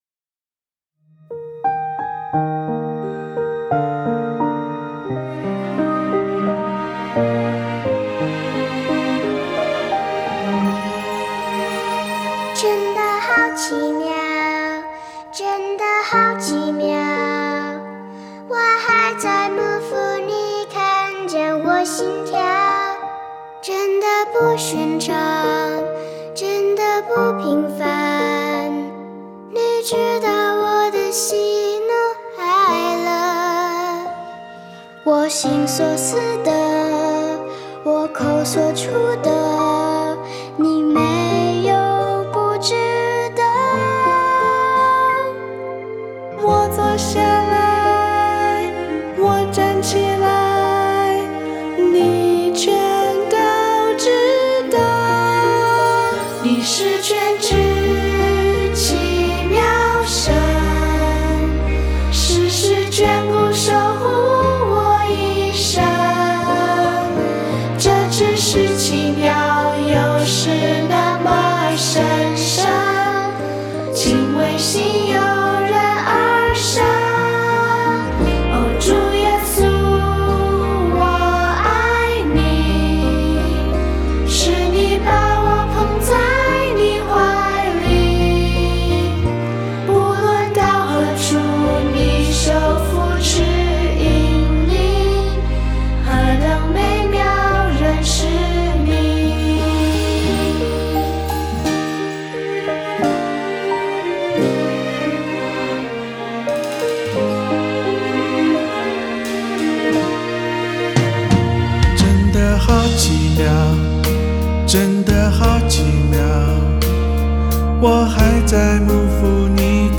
mp3 原唱音樂